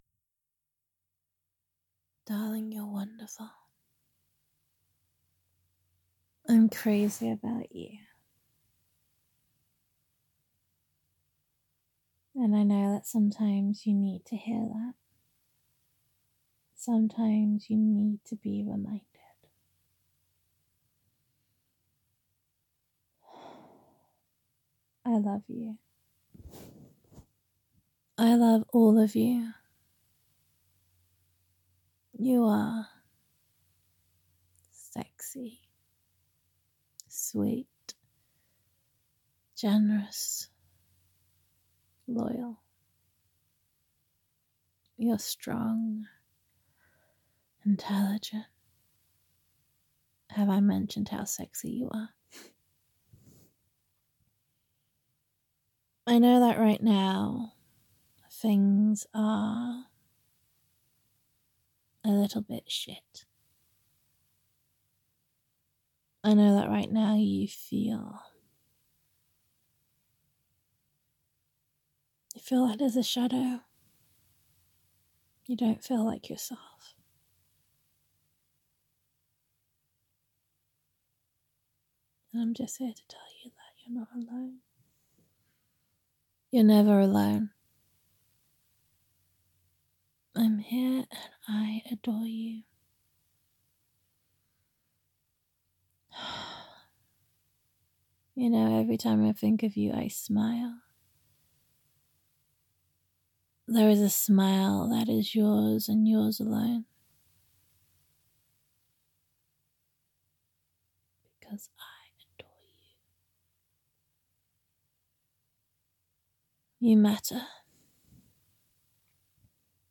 [F4A] Just a Reminder [You Are Amazing][You Are Not Alone][Please Listen to This Whenever You Need It][Adoring Comfort][Gender Neutral][Loving Girlfriend Voicemail]